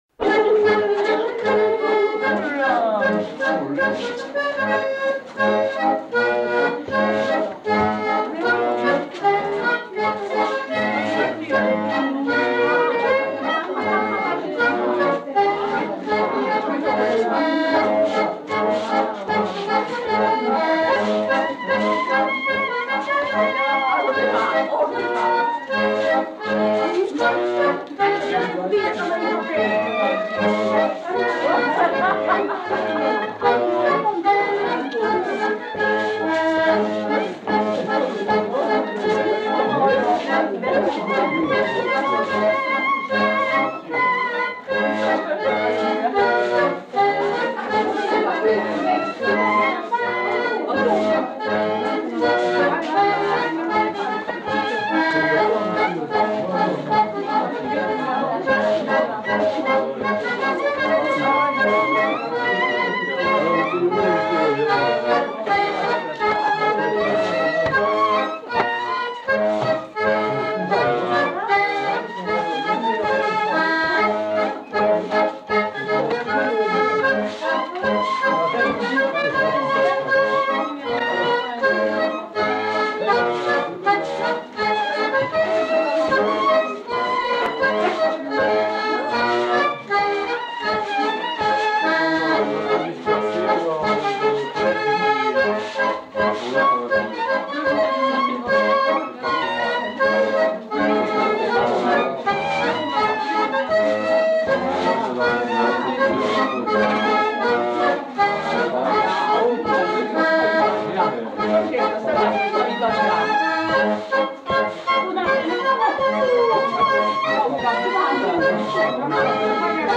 Scottish
Genre : morceau instrumental
Instrument de musique : violon ; accordéon diatonique
Danse : rondeau ; scottish